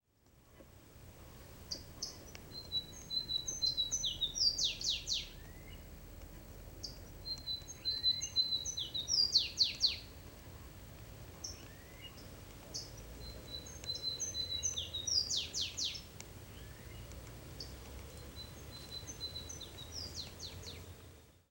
Canário-do-mato (Myiothlypis flaveola)
de fondo tambiénCrotophaga ani
Nome em Inglês: Flavescent Warbler
Condição: Selvagem
Certeza: Observado, Gravado Vocal
Myiothlypis-flaveola.mp3